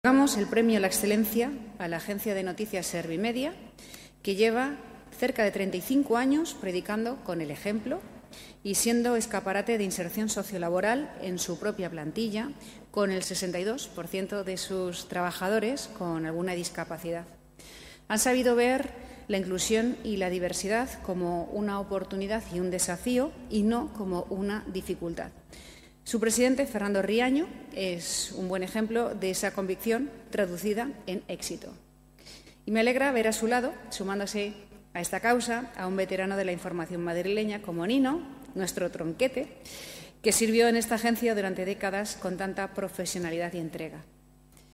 Por su parte, la presidenta de la Comunidad de Madrid afirmó en su discurso que Servimedia es